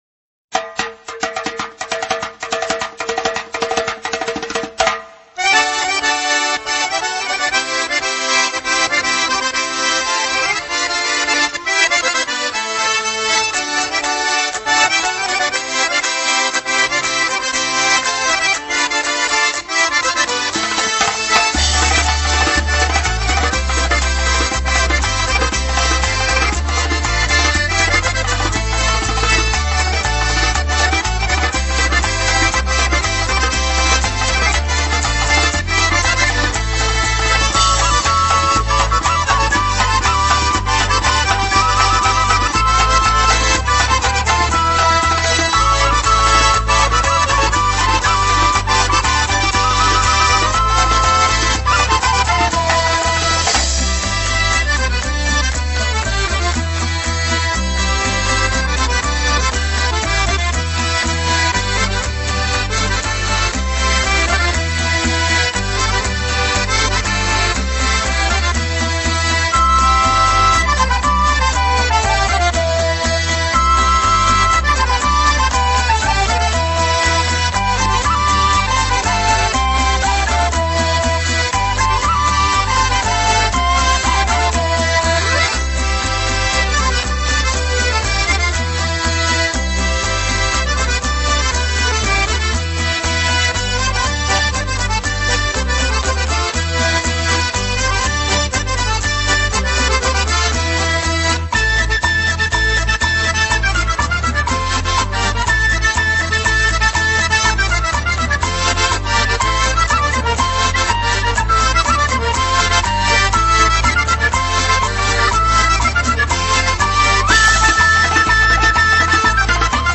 Akordeon ve Doli(koltuk davulu) resitali